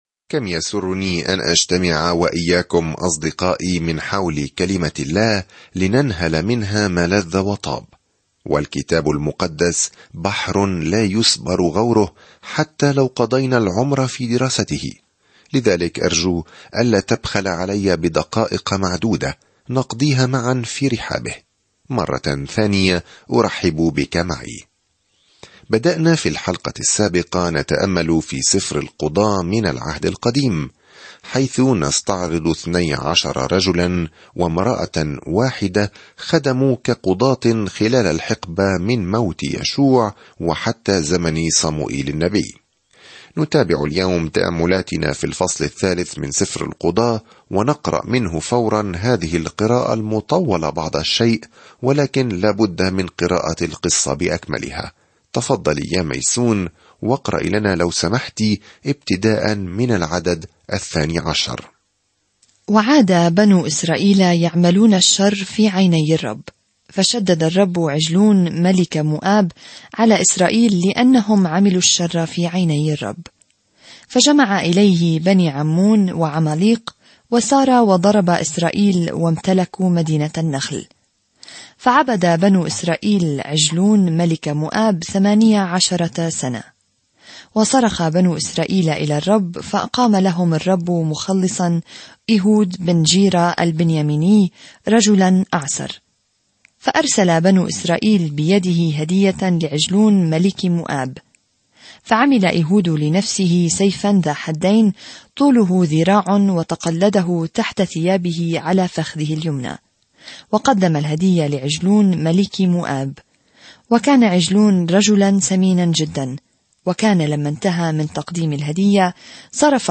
الكلمة اَلْقُضَاة 12:3-31 اَلْقُضَاة 4 اَلْقُضَاة 1:5-15 يوم 1 ابدأ هذه الخطة يوم 3 عن هذه الخطة يسجل القضاة الحياة الملتوية والمقلوبة أحيانًا للأشخاص الذين يستقرون في حياتهم الجديدة في إسرائيل. سافر يوميًا عبر القضاة وأنت تستمع إلى الدراسة الصوتية وتقرأ آيات مختارة من كلمة الله.